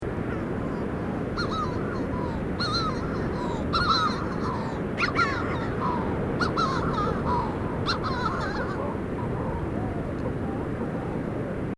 Manx Shearwater Recordings, July 2007, Co. Kerry, Ireland
wingbeats - landing